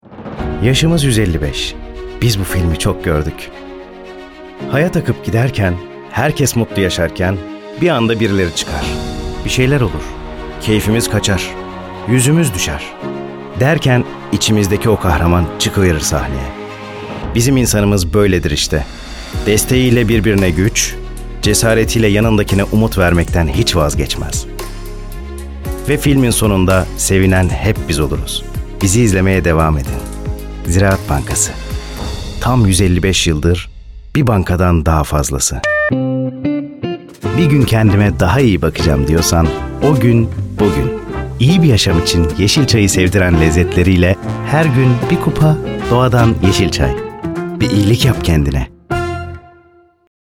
Reklam Demo